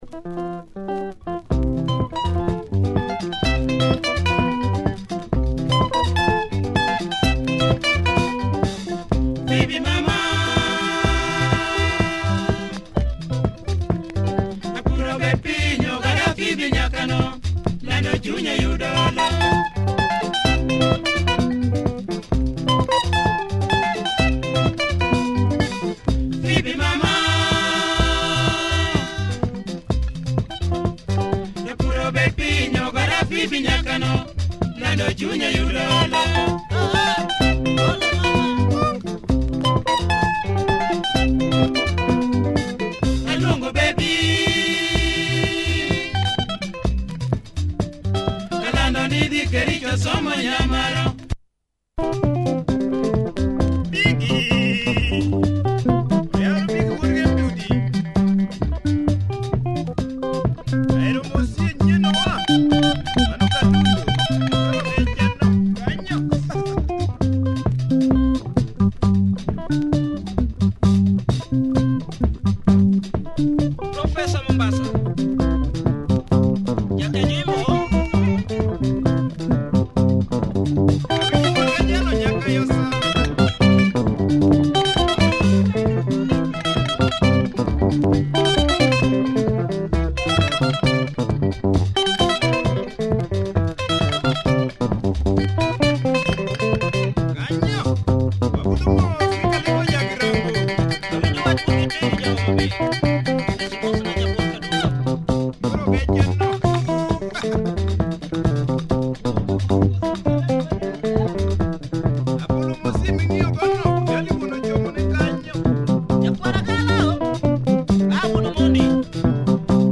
Great Luo benga from this prolific group